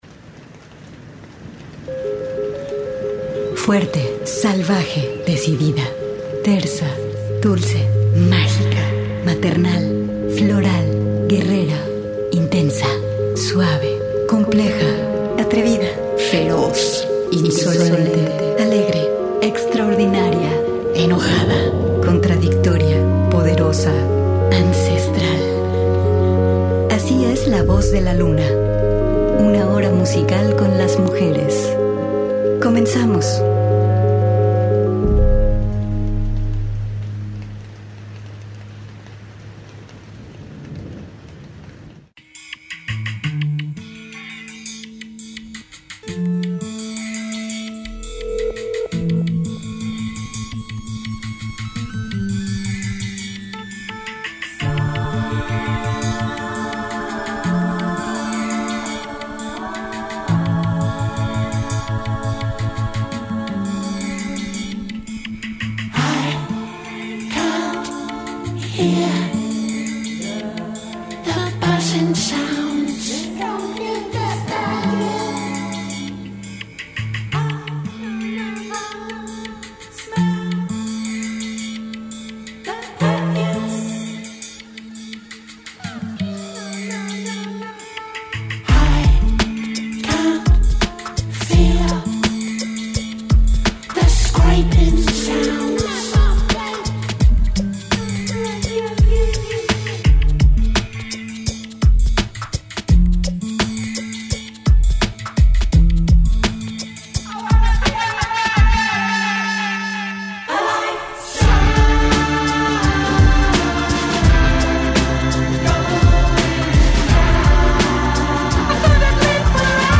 Hoy suena música hecha por mujeres desde distintas partes del mundo.